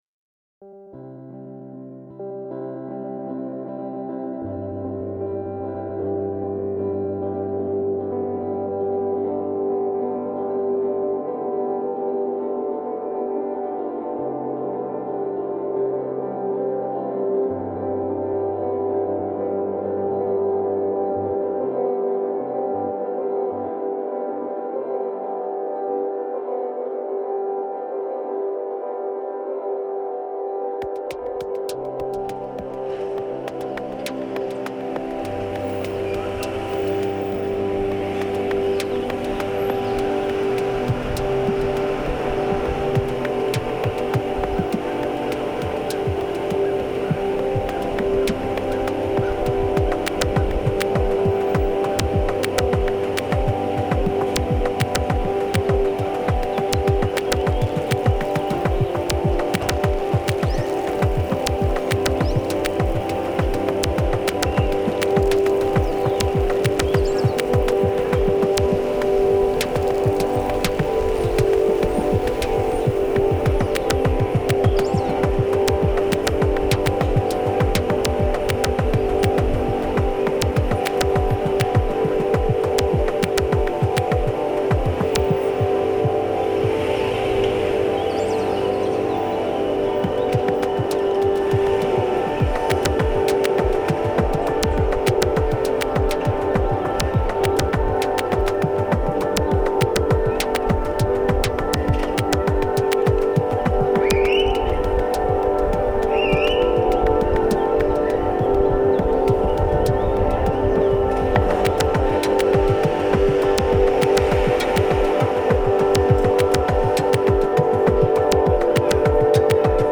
guitar
laptop
field recordings